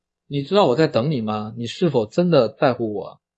为了限制ChatTTS的使用，我们在4w小时模型的训练过程中添加了少量额外的高频噪音，并用mp3格式尽可能压低了音质，以防不法分子用于潜在的犯罪可能。
没有意外的话，你应该能听到比较真实的人类声音。